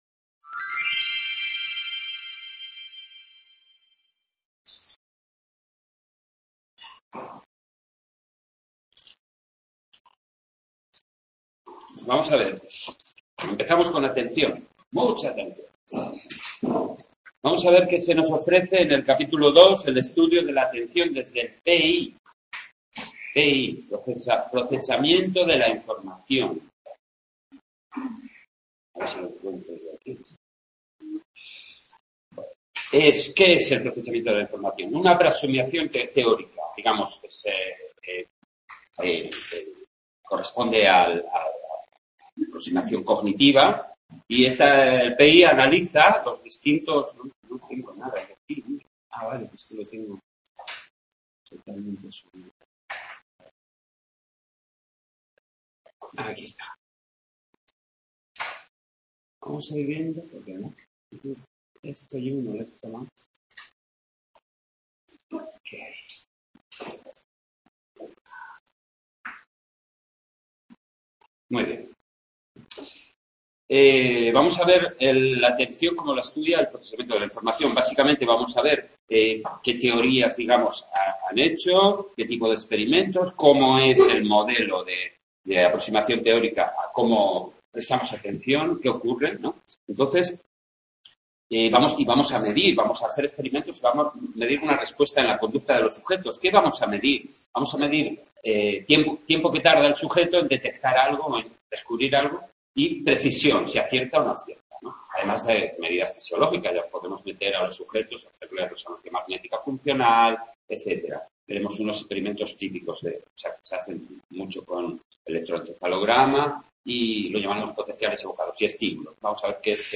en el C.A. UNED de Sant Boi